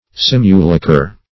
Search Result for " simulacher" : The Collaborative International Dictionary of English v.0.48: Simulacher \Sim"u*la`cher\, Simulachre \Sim"u*la`chre\, n. [Cf. F. simulacre.]